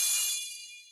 Melee Sword Sounds
Attack Unblockable Effect Sound.wav